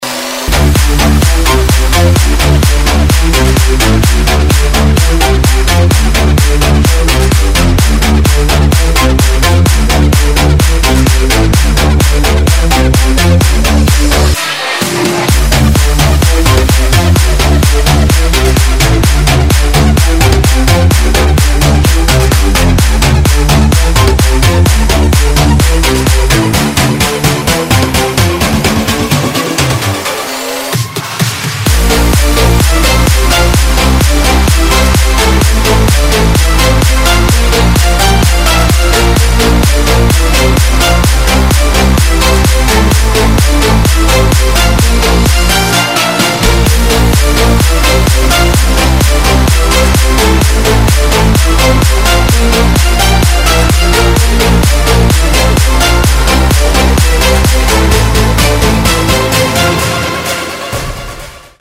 progressive house
Позитивно-торжественный трек!